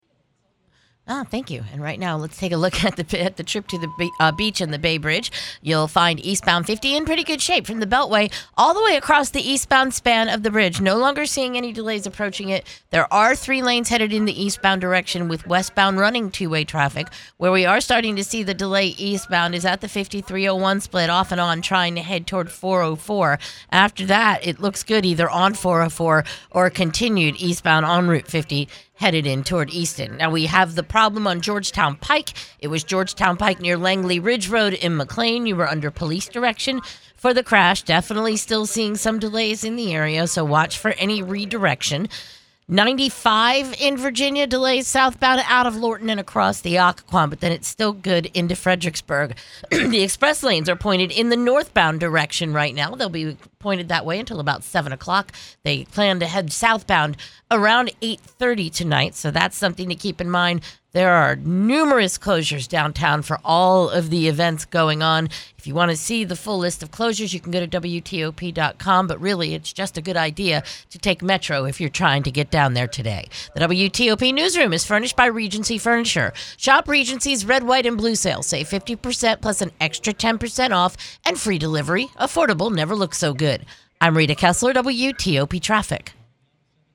Recent Traffic Report.